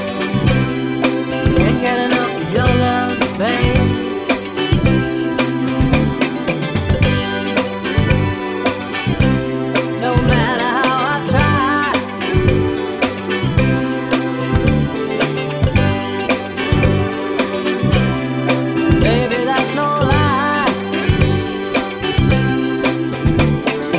Two Semitones Up Disco 3:49 Buy £1.50